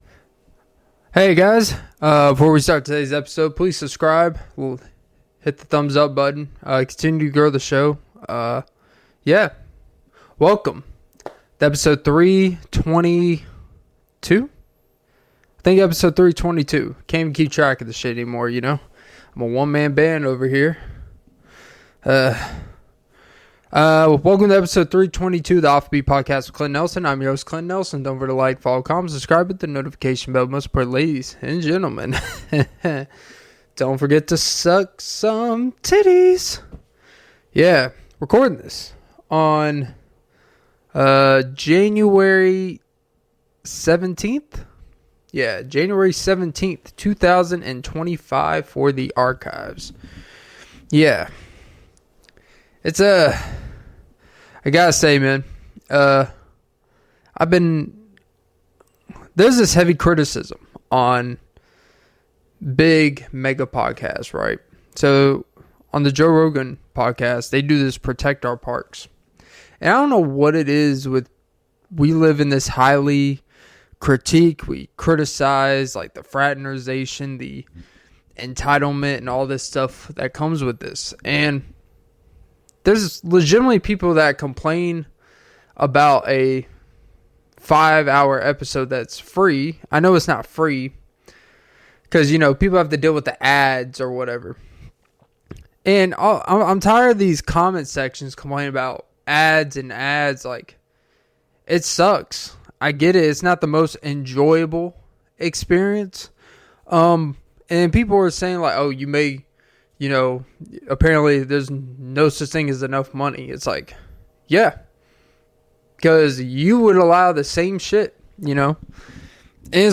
Comedy Podcast on Spotify & All Platforms.